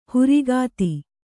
♪ hurigāti